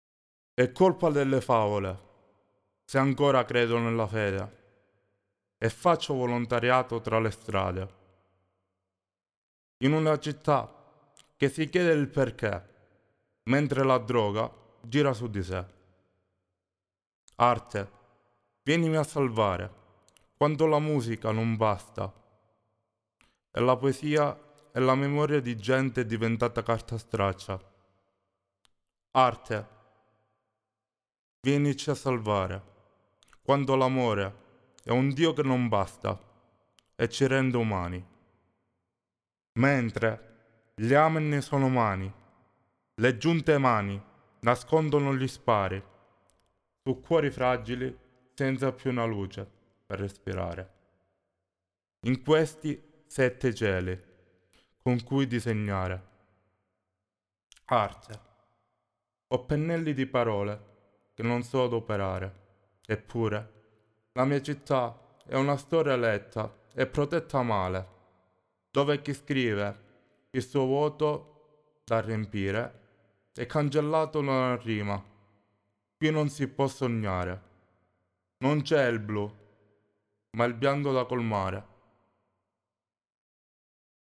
ecco i bianchi (solo voce)